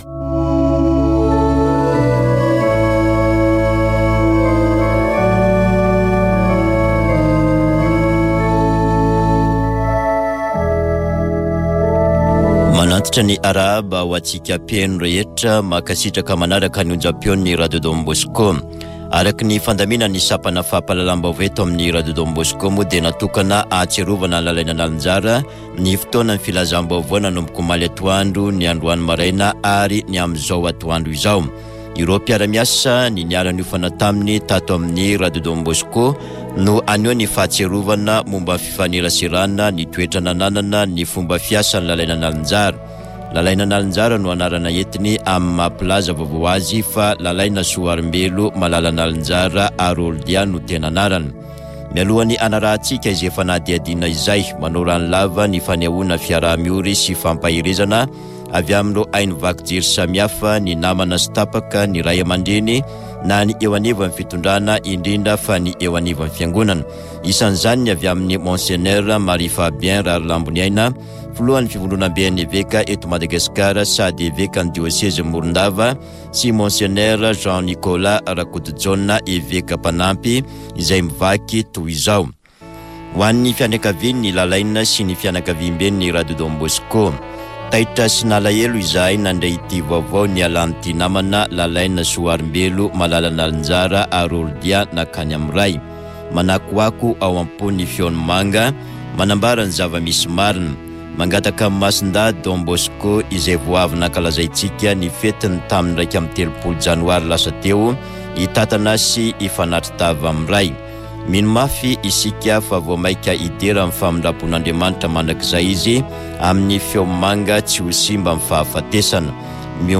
[Vaovao antoandro] Talata 6 febroary 2024